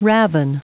Prononciation du mot raven en anglais (fichier audio)
Prononciation du mot : raven